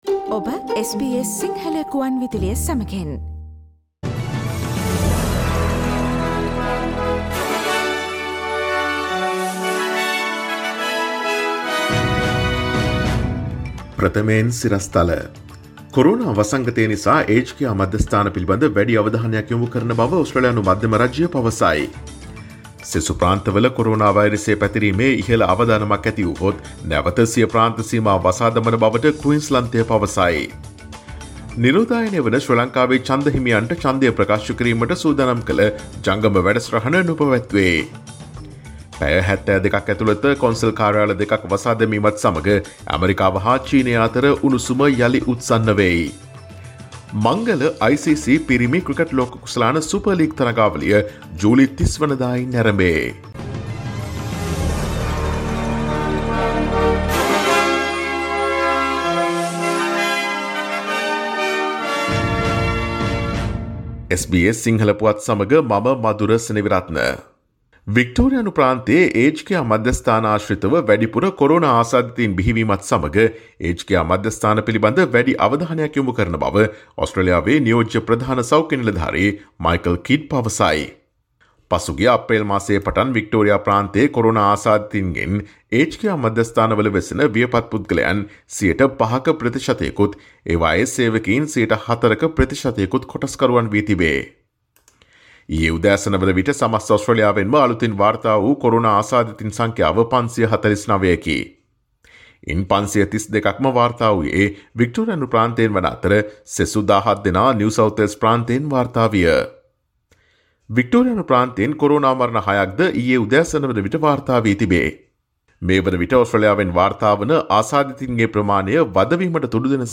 Daily News bulletin of SBS Sinhala Service: Tuesday 28 July 2020
Today’s news bulletin of SBS Sinhala Radio –Tuesday 28 July 2020 Listen to SBS Sinhala Radio on Monday, Tuesday, Thursday and Friday between 11 am to 12 noon